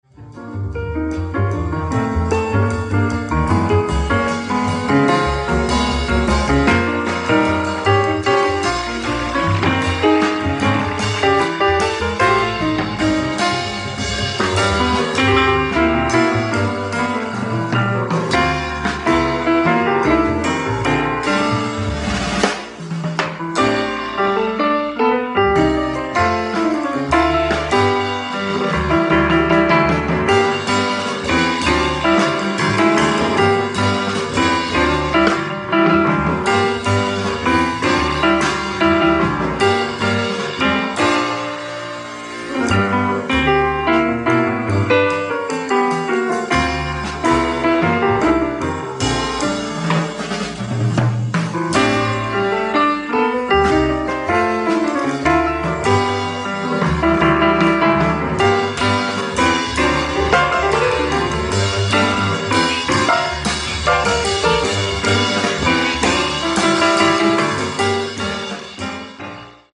Tracks 1-4:ライブ・アット・ルガーノ、スイス 10/15, 16, 17/1969
レストアされたサウンドボード音源！！
※試聴用に実際より音質を落としています。